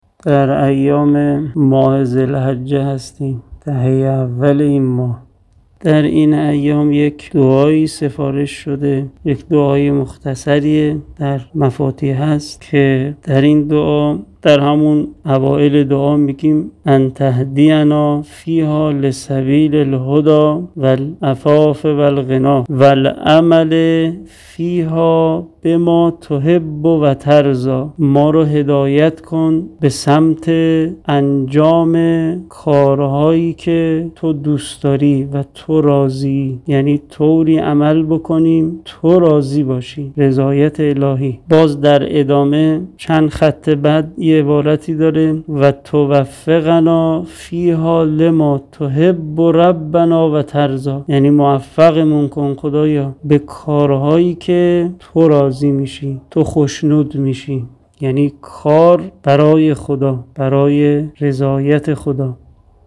سخنرانی کوتاه